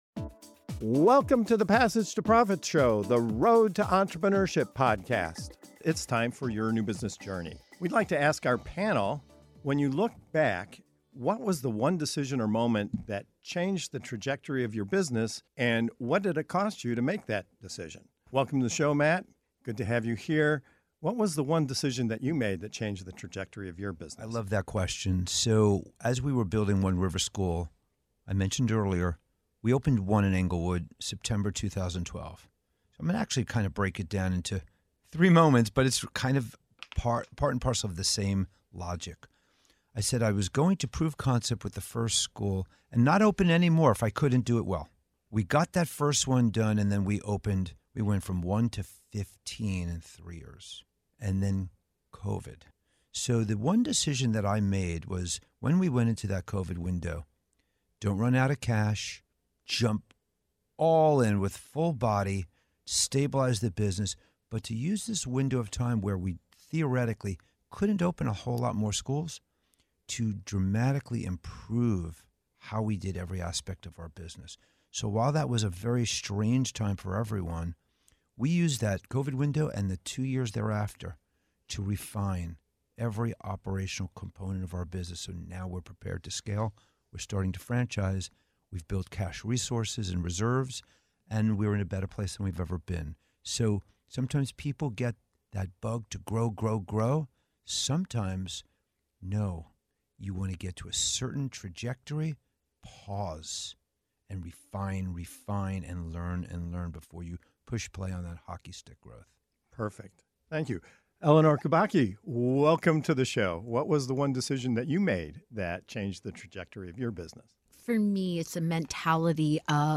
In this segment of "Your New Business Journey" on Passage to Profit Show, entrepreneurs share the pivotal moments that shaped their success—from pausing growth during COVID-19 to refine operations, to saying yes to unexpected opportunities that led to major brand breakthroughs, to launching a podcast that unlocked new business ventures. Learn how strategic patience, smart risk-taking, and leveraging new platforms can position your business for long-term growth and scalability.